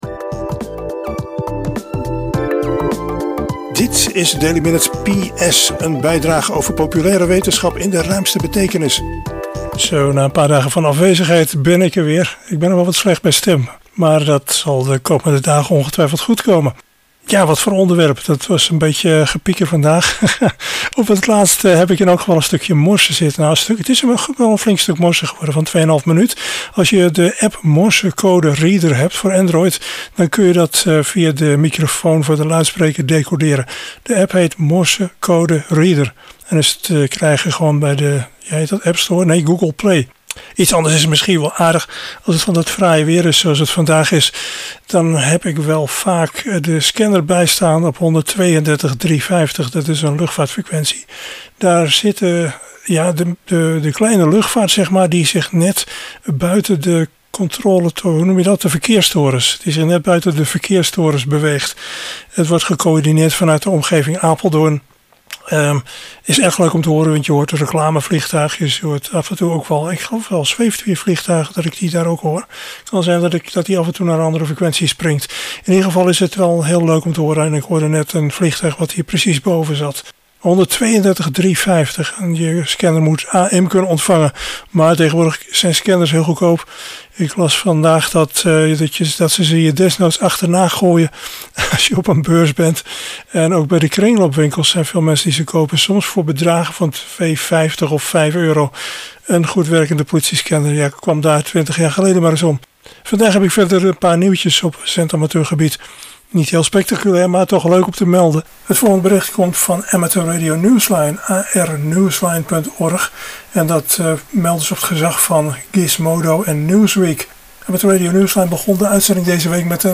Zendamateurnieuws en een stukje geseinde morse S2E471